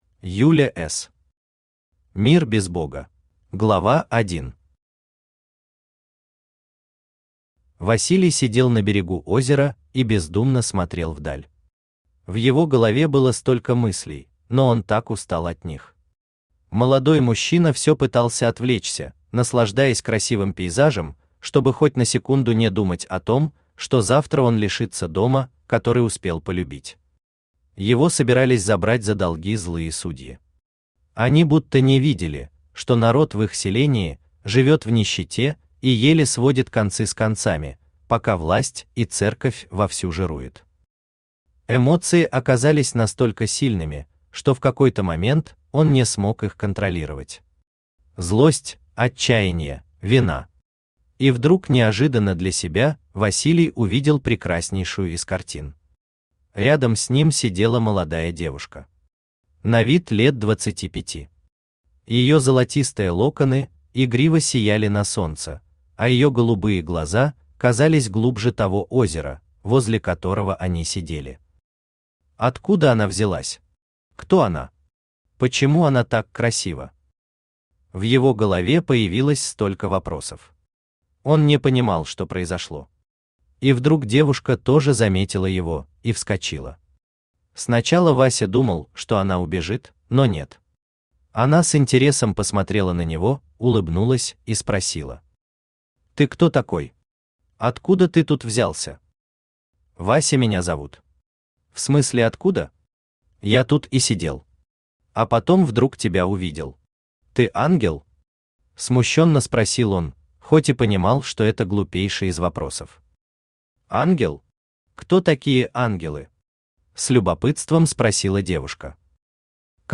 Аудиокнига Мир без бога | Библиотека аудиокниг
Читает аудиокнигу Авточтец ЛитРес.